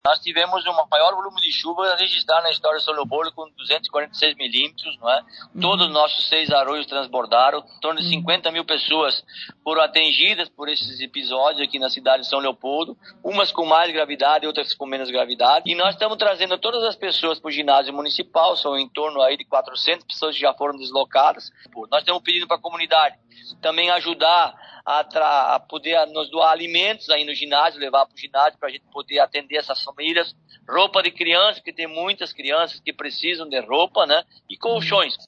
Sete prefeitos ouvidos pela reportagem da Guaíba relatam os dramas enfrentados pelas diferentes regiões do Estado
O município também registrou dois óbitos, sendo um por descarga elétrica e outro porque a vítima teve o veículo arrastado ao tentar cruzar uma ponte. O prefeito Ary Vanazzi reforça ter sido o pior temporal já registrado na cidade, e solicita doações.